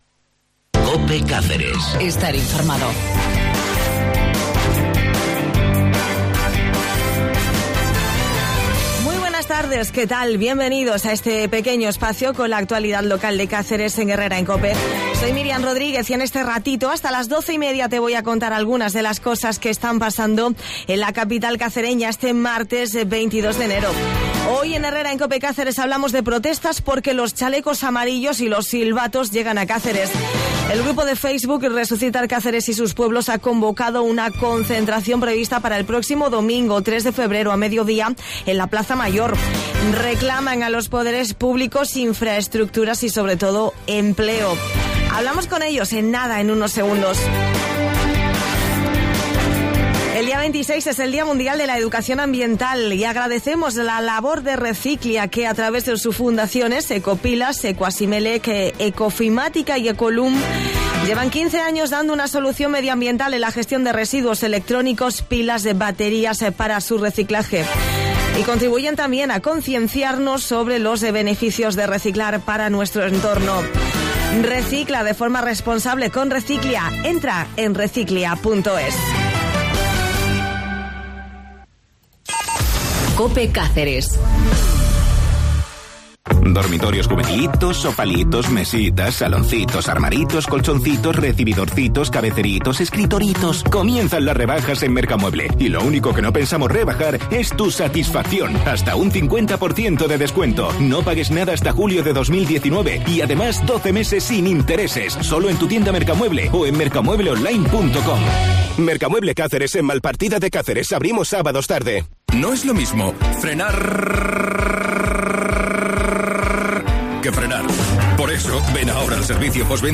Entrevista al grupo de Facebook Recuperar Cáceres y sus pueblos